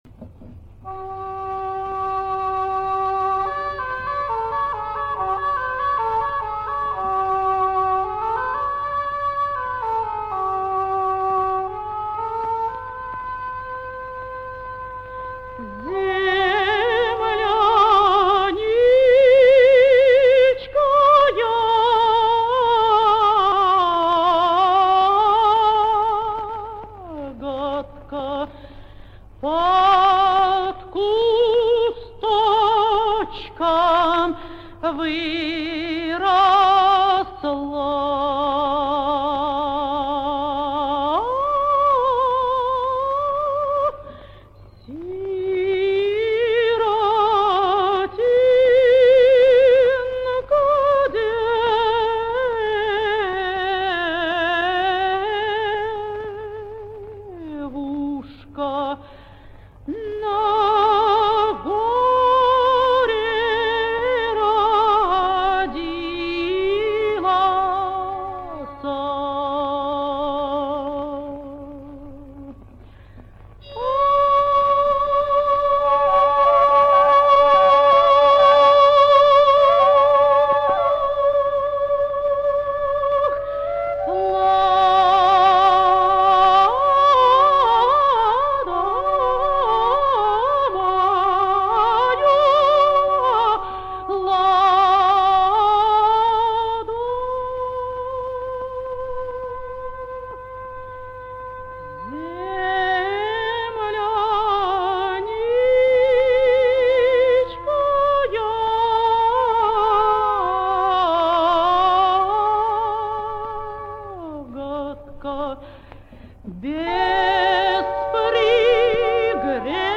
мультфильм СНЕГУРОЧКА по опере Н.Римского-Корсакова (1952г.)
хор и танец птиц "Собрались птицы" - музыка